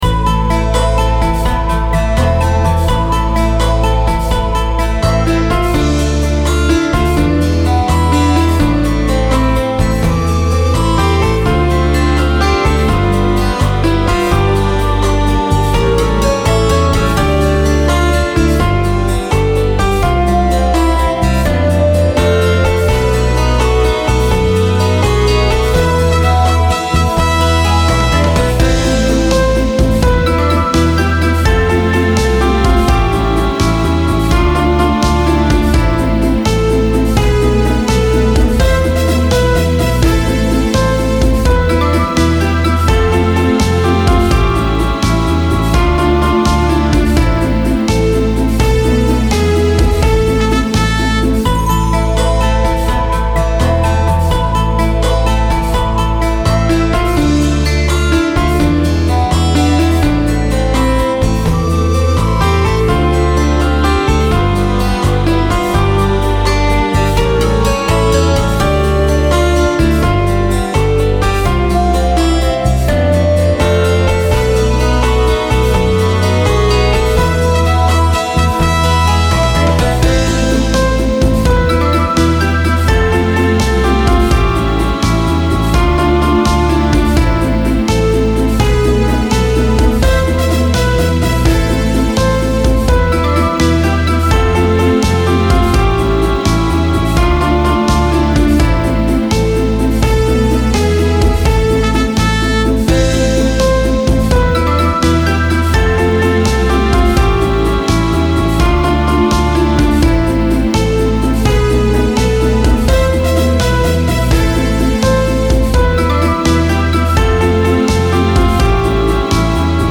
• Категория: Детские песни
караоке